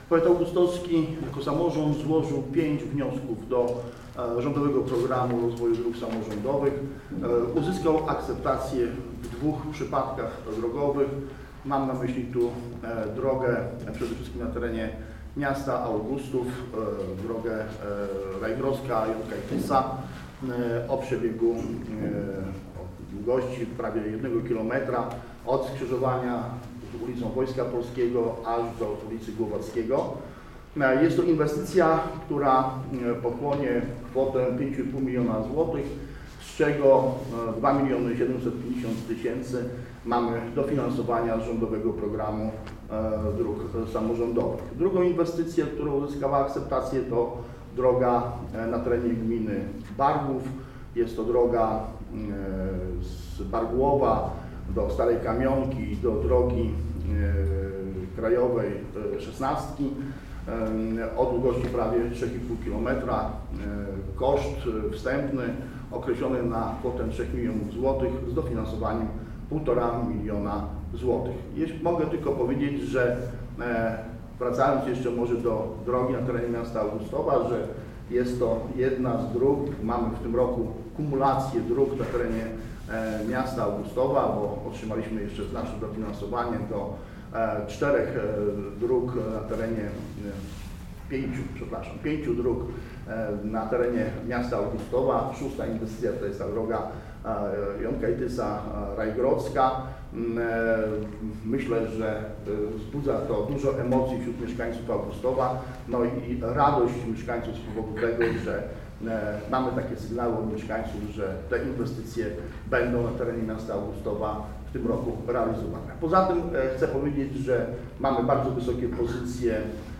We wtorek, 22 lutego w Starostwie Powiatowym w Suwałkach odbyła się konferencja dotycząca projektów, które uzyskały dofinansowanie.
Dariusz Szkiłądź, wicestarosta augustowski o planowanych inwestycjach: